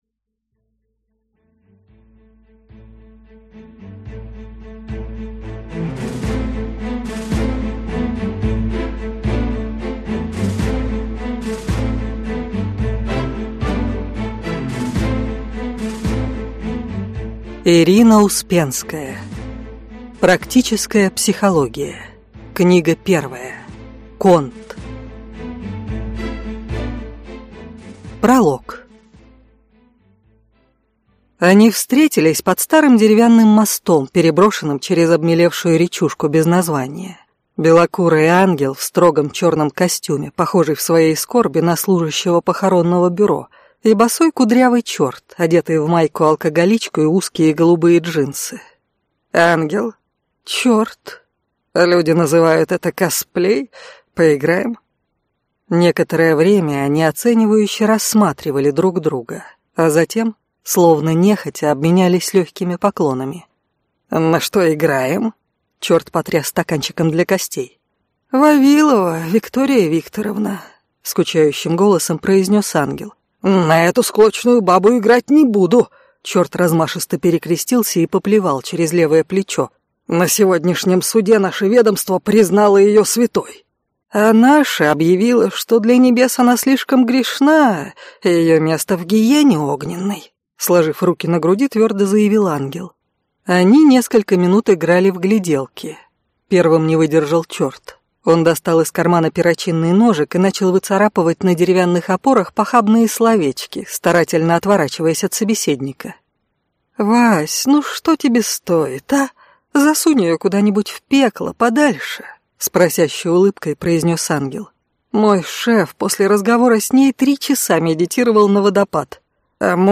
Аудиокнига Практическая психология. Конт | Библиотека аудиокниг